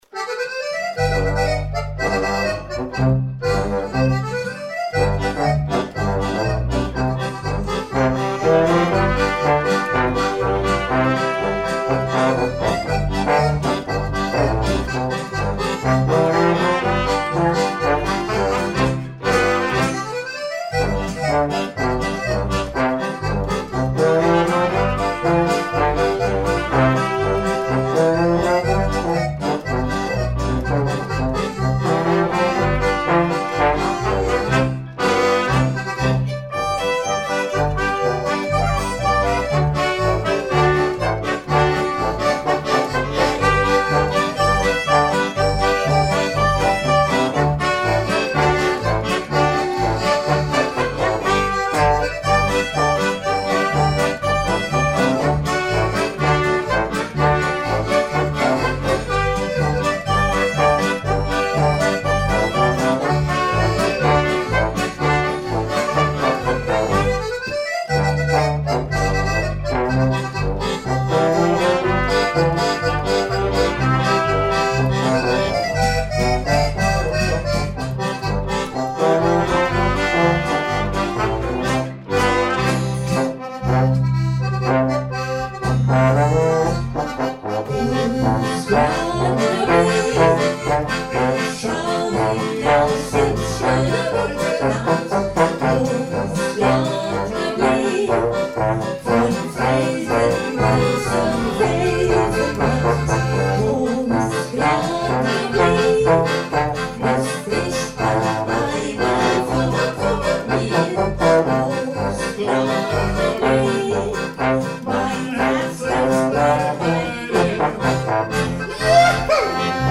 Proberaumaufnahme: Großglocknerblick
grossglockner-probenaufnahme.mp3